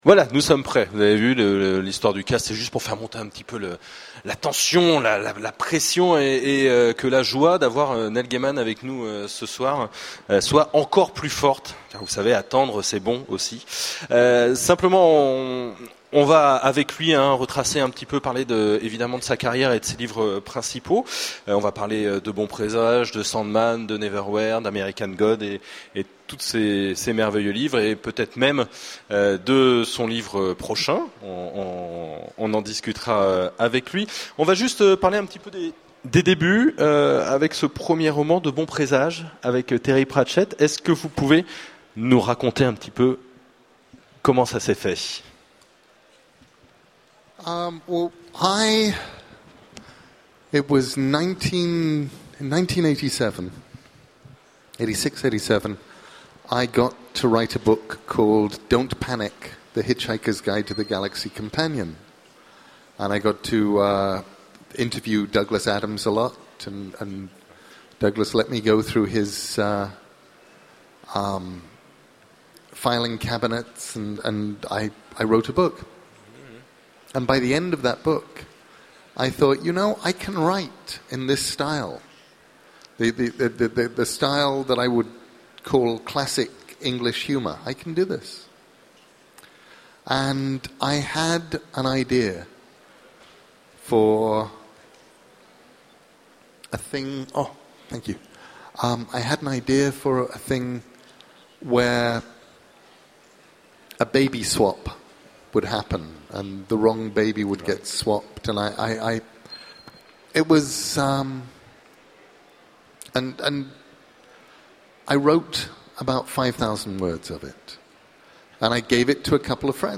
Les archives Actusf : Utopiales 12 : Conférence Rencontre avec Neil Gaiman
On en profite pour vous présenter nos plus belles archives dont cette chouette interview aux Utopiales 2012 de Neil Gaiman.